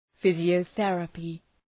Προφορά
{,fızıəʋ’ɵerəpı}